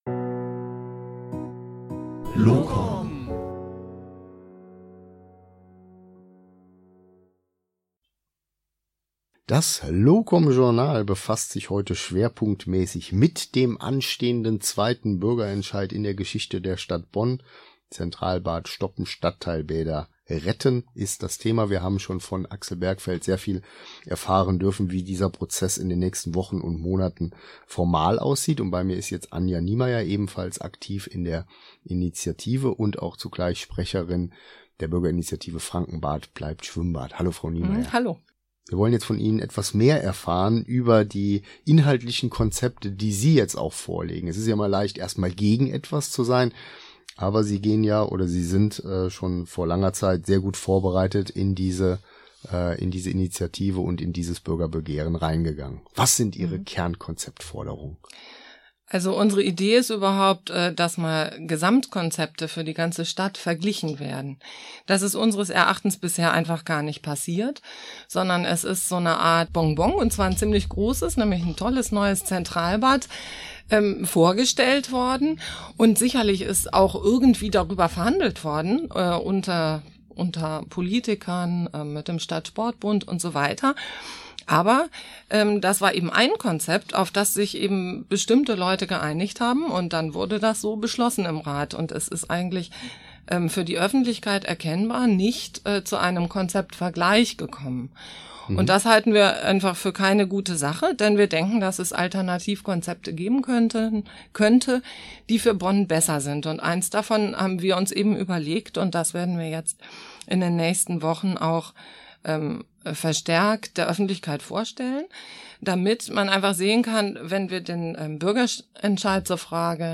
Wir waren am 28.05.2018 im Bürgerradio auf Radio Bonn/Rhein-Sieg, präsentiert von LoCom.